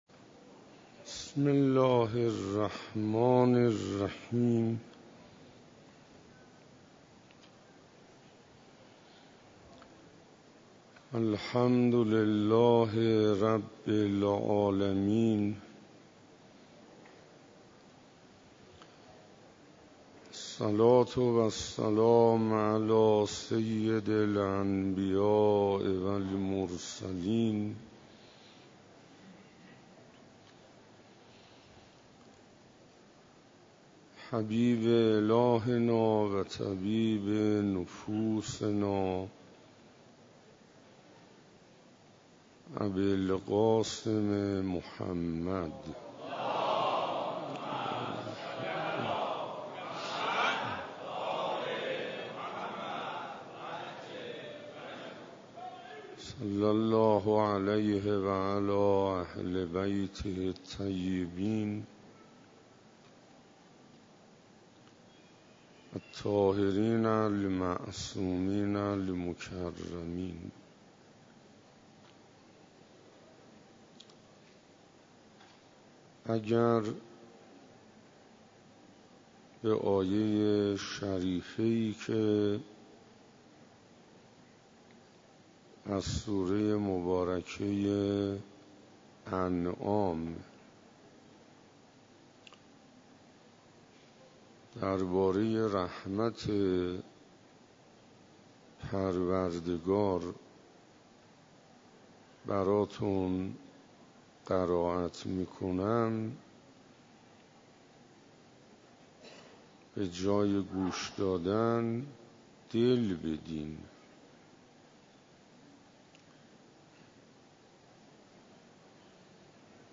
روز 24 رمضان97 - مسجد امیر علیه السلام - رمضان